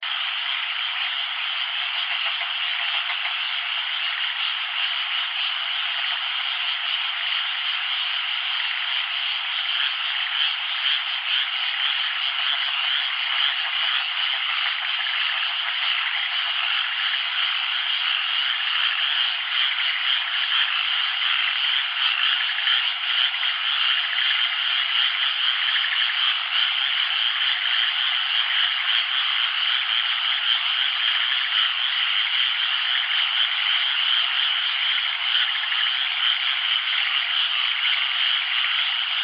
田舎に住んでいる人にはおなじみのカエルの大合唱です。カエルたちのコーラスにきっと癒されることでしょう。